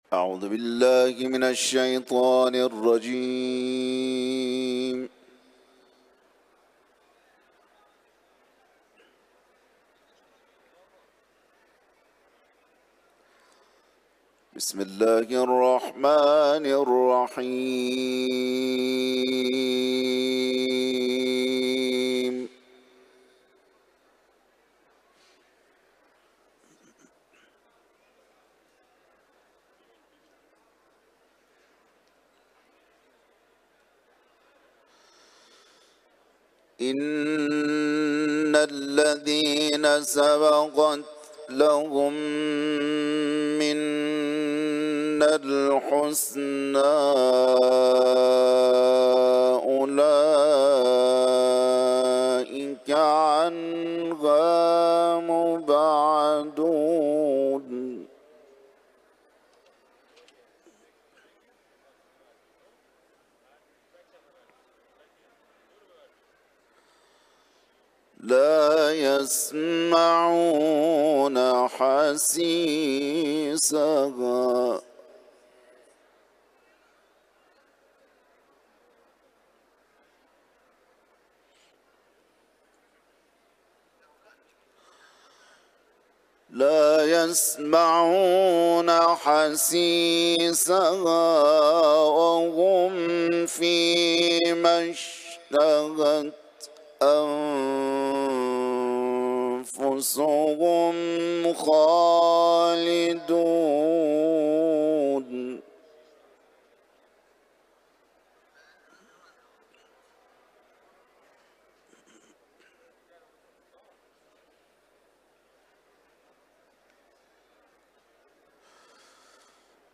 Kur’an-ı Kerim’den ayetler tilavet etti